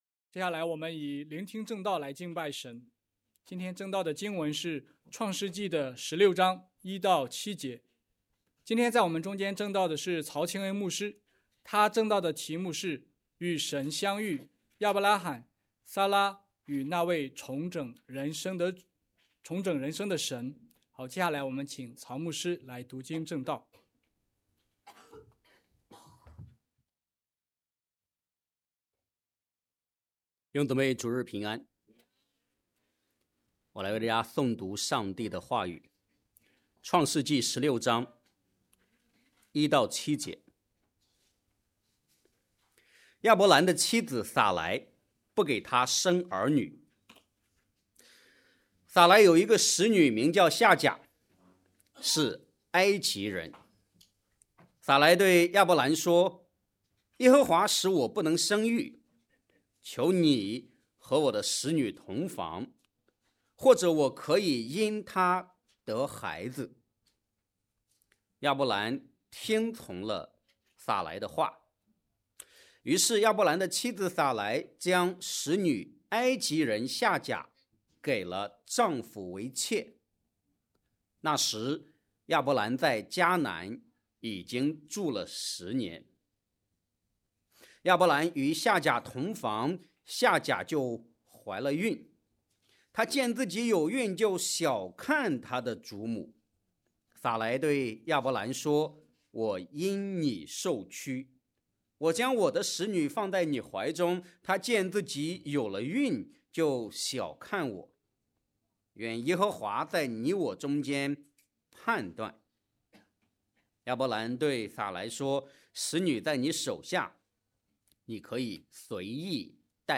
Home / 证道 / 与神相遇 / 与神相遇：亚伯拉罕、撒拉与那位重整人生的神